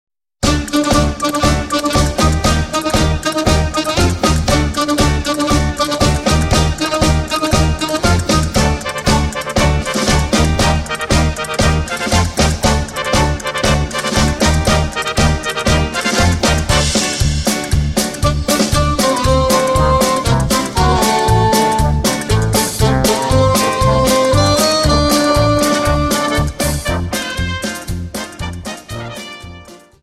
Dance: Paso Doble Song